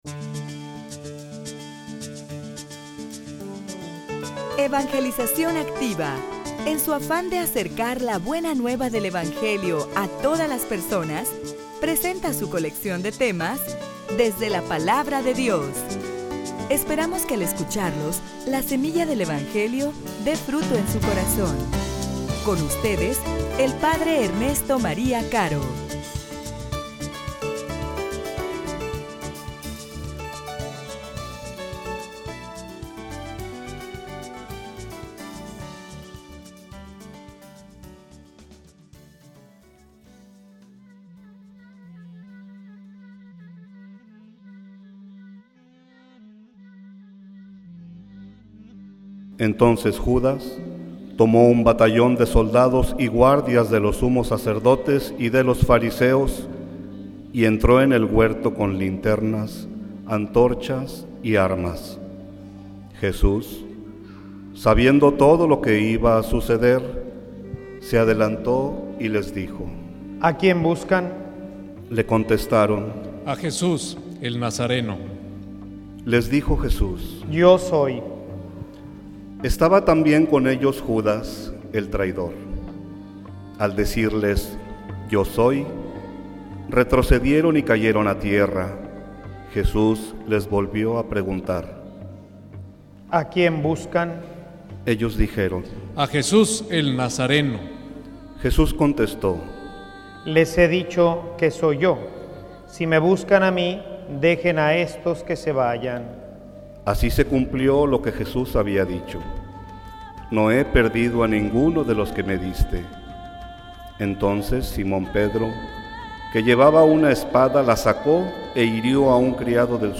homilia_Las_Consecuencias_de_la_fidelidad.mp3